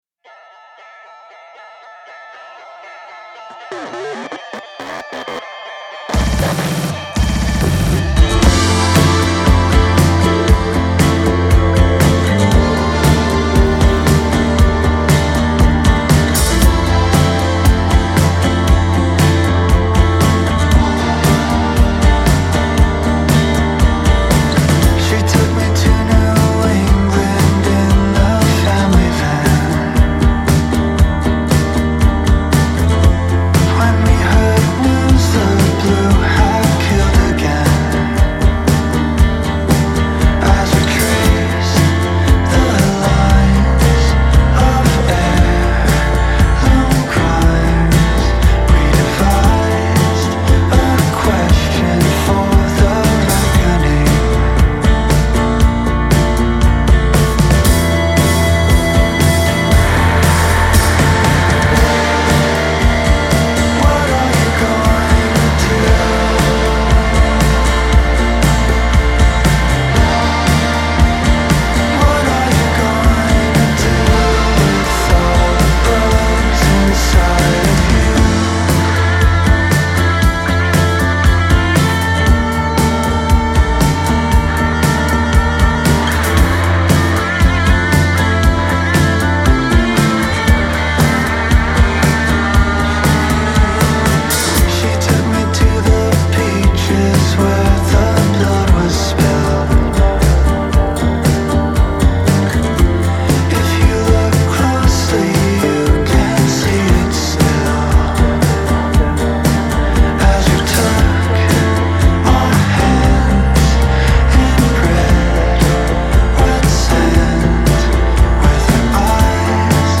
indie rock band
which is a fresh take on 90s/Early 00s style indie rock.